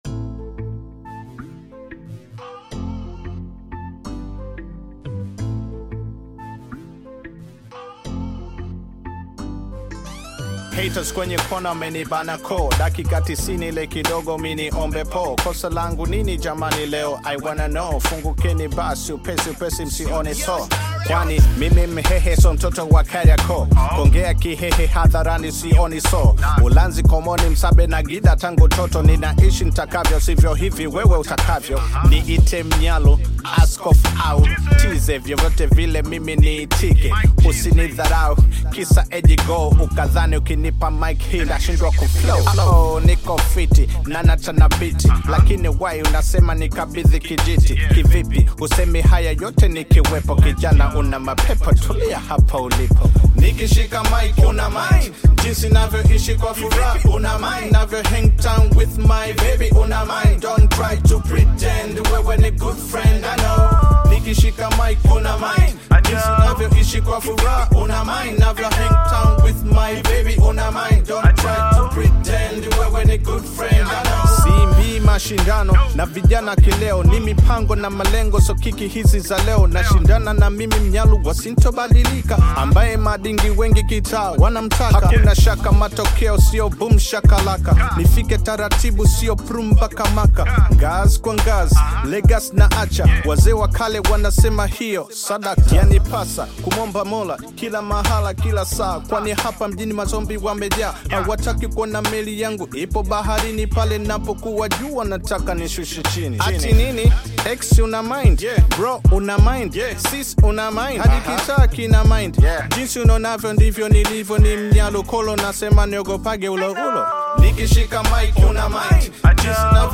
Veteran Bongo Flava artist
he released his latest rap song today, featuring singer
African Music